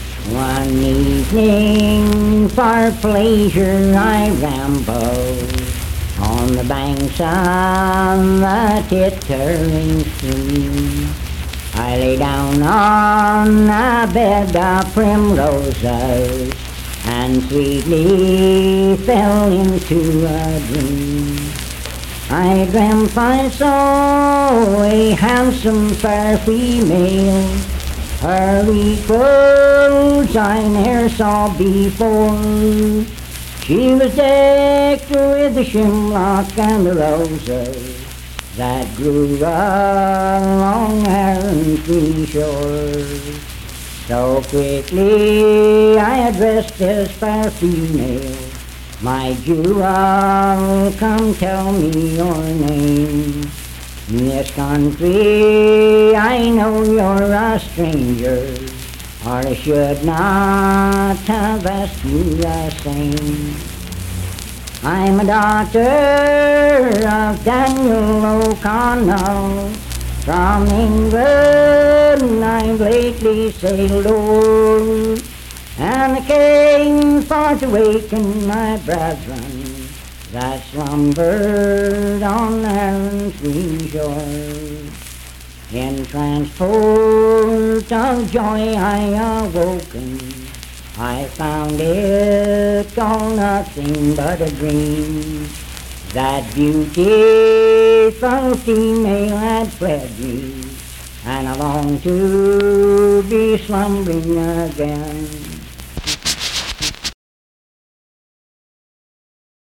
Unaccompanied vocal music
Verse-refrain 3(4-8).
Performed in Sandyville, Jackson County, WV.
Voice (sung)